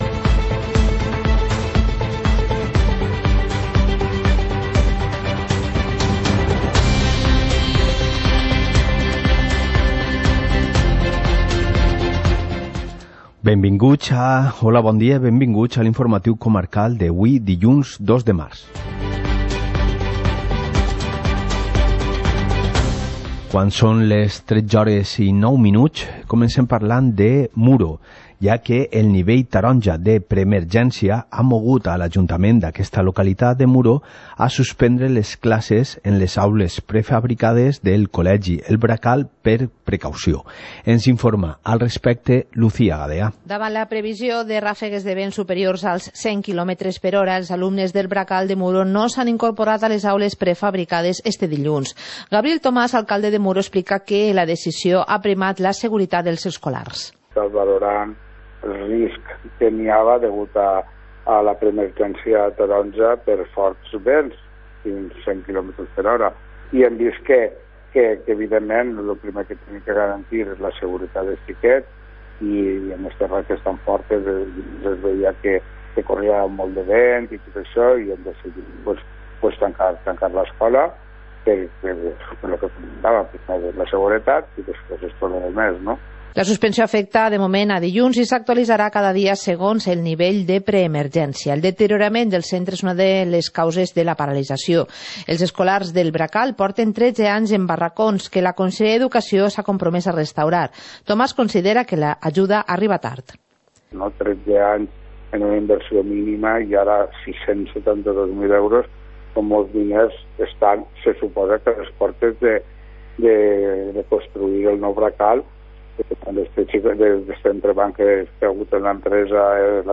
Informativo comarcal - lunes, 02 de marzo de 2020